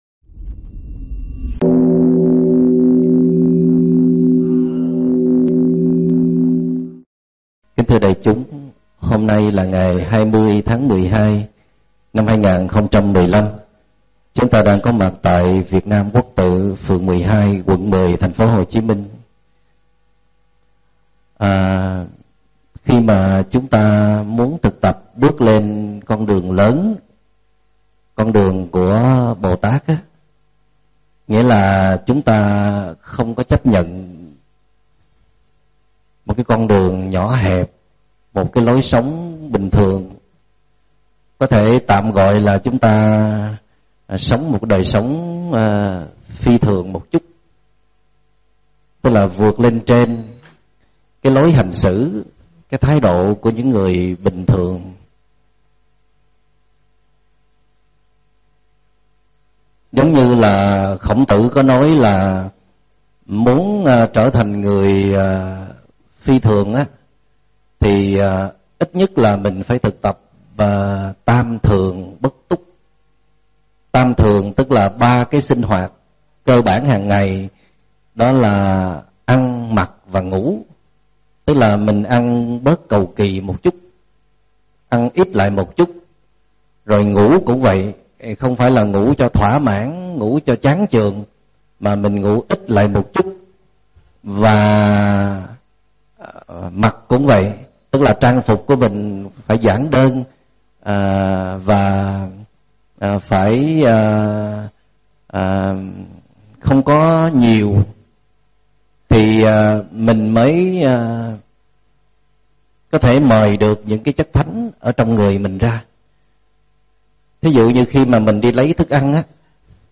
Mời quý phật tử nghe mp3 thuyết pháp Giới Bồ Tát
Mp3 Thuyết Pháp